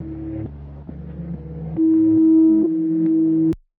melody (used in the begining of the verse).wav